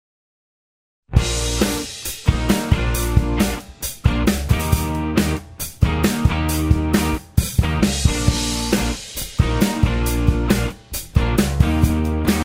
It's working about as well as its going to in that mix and doesn't sound bad by any means.
And as many people have posted already, the amount of ring is a matter of taste anyway.
Just listening on the computer speakers at work, there is nothing wrong with it at all.